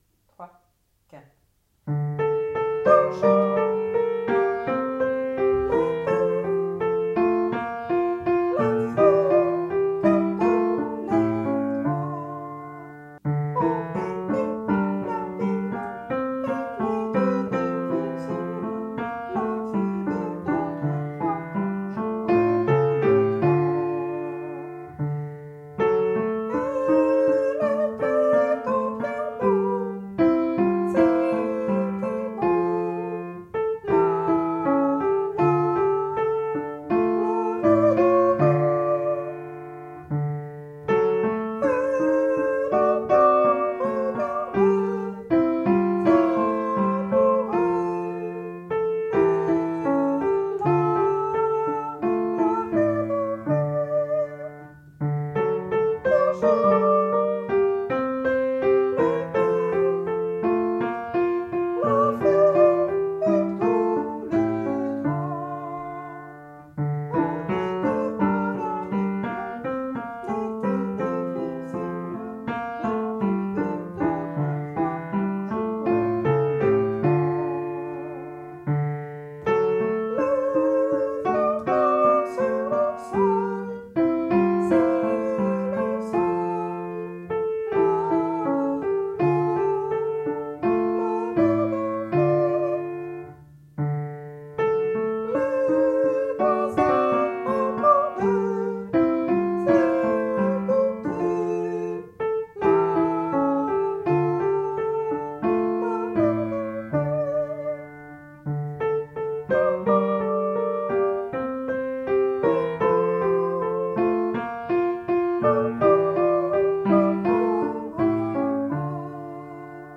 voix sopranes, au ralenti
petite-fugue-S-lent.mp3